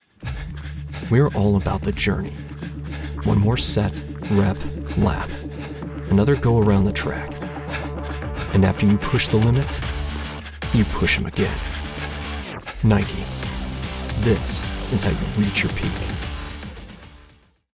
English - Midwestern U.S. English
Midwest, Neutral
Young Adult
Middle Aged